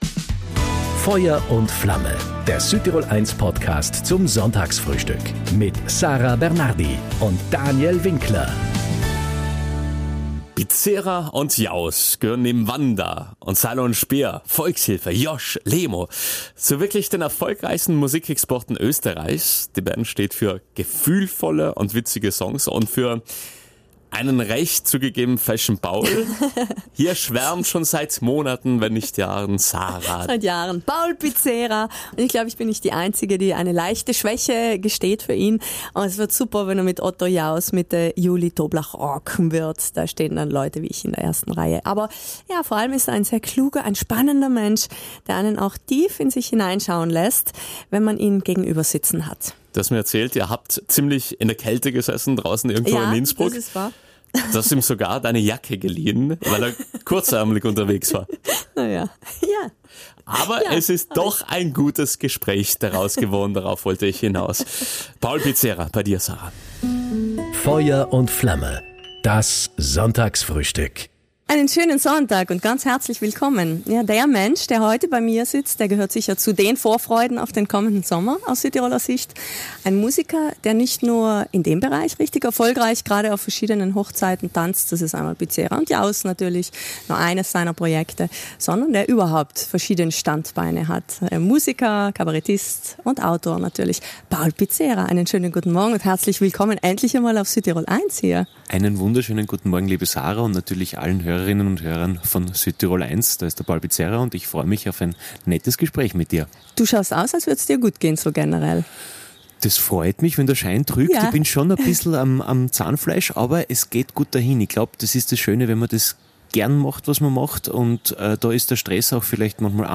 Musiker und Kabarettist Paul Pizzera
im Gespräch